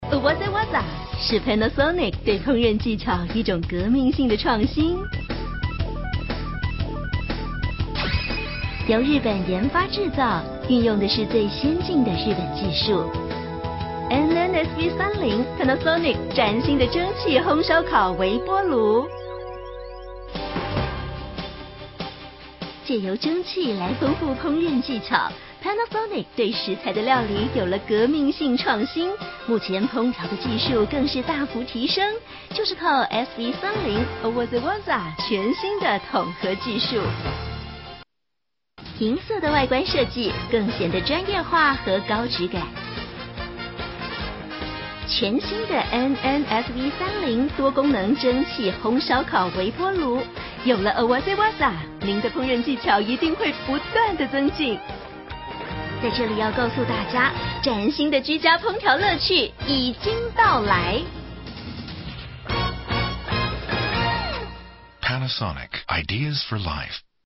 台語配音 國語配音 女性配音員 客語配音
(旁白-賣場產品說明 國)Panasonic微波爐(60秒)
她亦擅長年輕女性、母性角色及莊重旁白語調，廣受廣播劇與政府標案製作青睞。
旁白-賣場產品說明-國Panasonic微波爐60秒.mp3